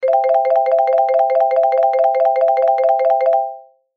8. Электронный игровой автомат — увеличение суммы выигрыша
djekpot.mp3